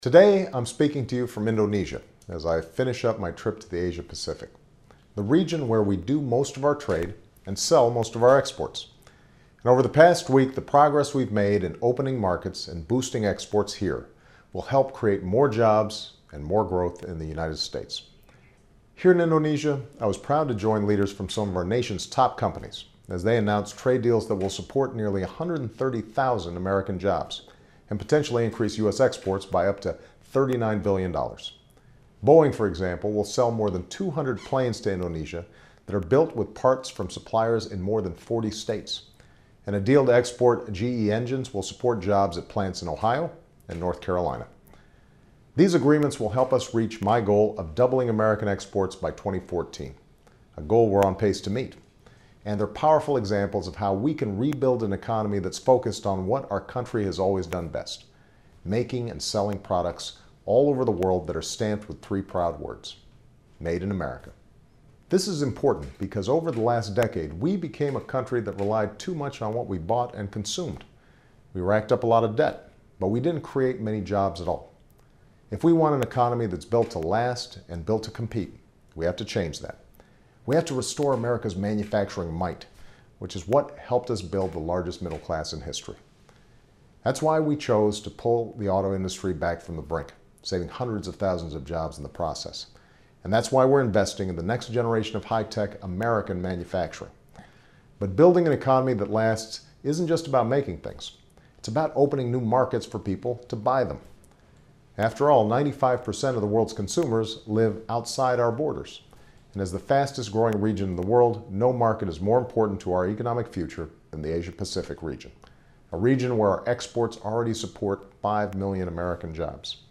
Remarks of President Barack Obama
Bali, Indonesia